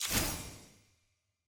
sfx-eog-ui-bronze-burst.ogg